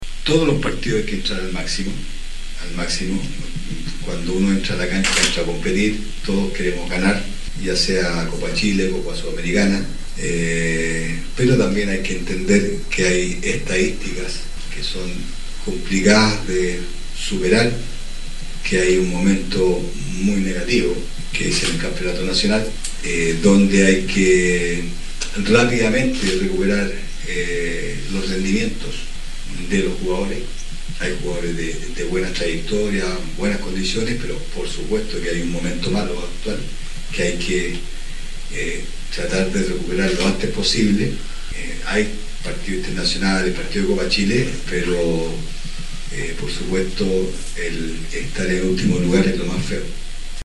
En conferencia de prensa, el técnico envió un saludo a los hinchas, resaltando la importancia de la comunicación con la comunidad y el sentido de pertenencia en la ciudad nortino.